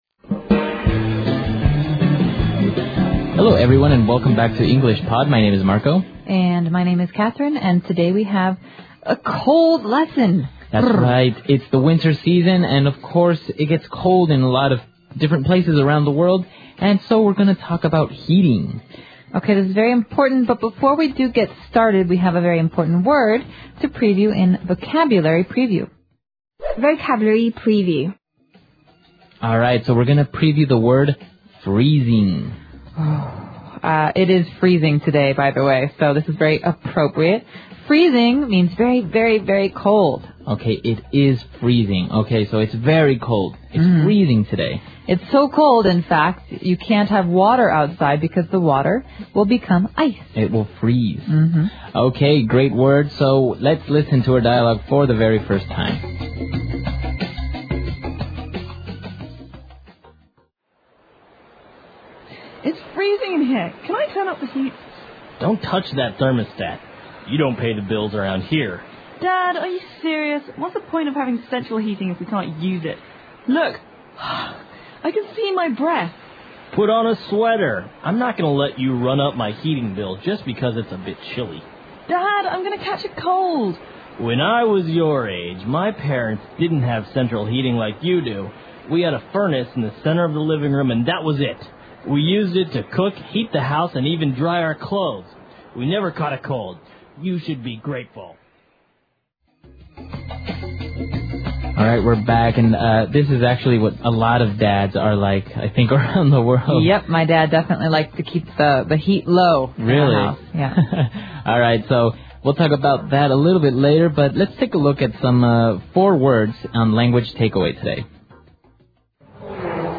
纯正地道美语(外教讲解)173：取暖系统 听力文件下载—在线英语听力室